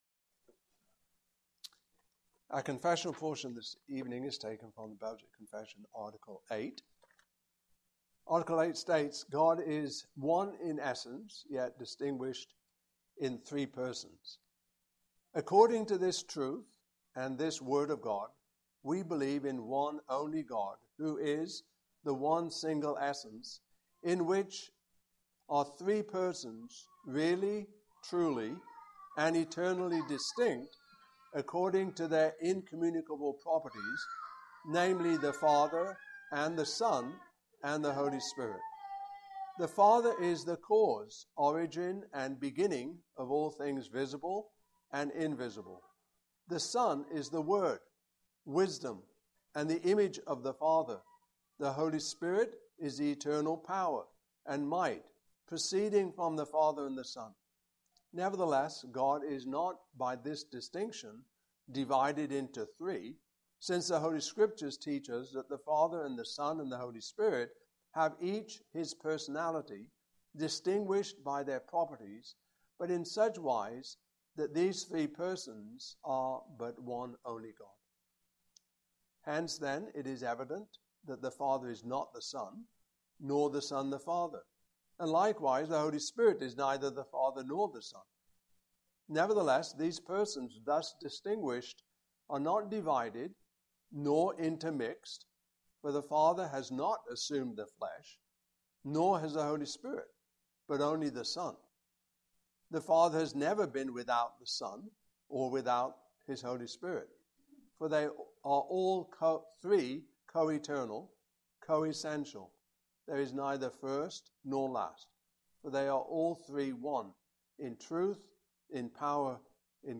Belgic Confession 2025 Passage: Acts 17:13-34 Service Type: Evening Service Topics